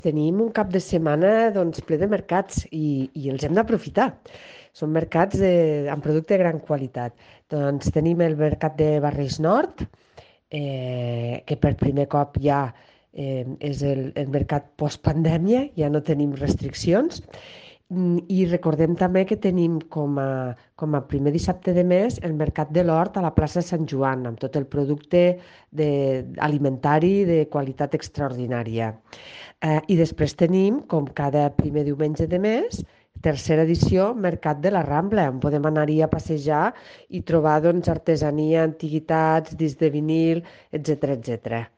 tall-de-veu-de-la-regidora-marta-gispert-sobre-els-mercats-daquest-cap-de-setmana-a-lleida